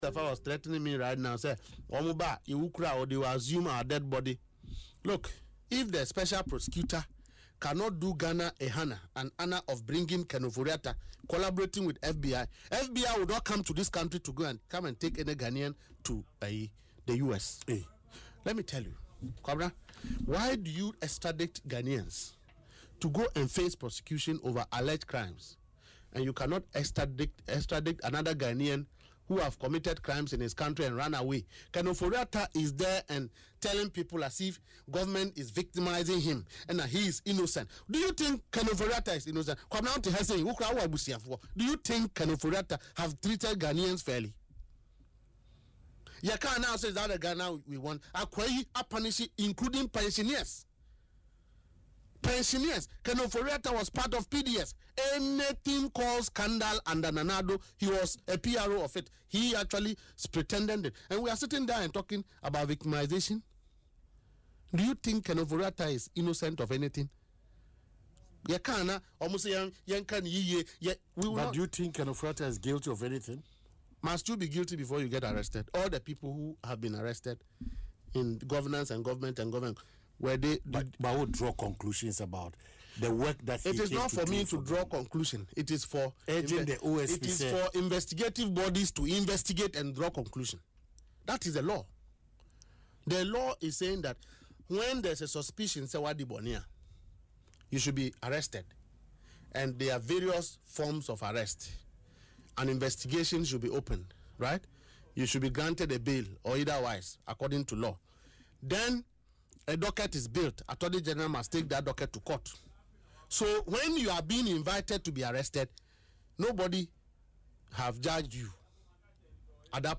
Speaking in an interview on Asempa FM’s Ekosii Sen, Mr. Gbande questioned why the Office of the Special Prosecutor (OSP) has not collaborated with international bodies like the U.S. Federal Bureau of Investigation (FBI) to hold the former minister accountable.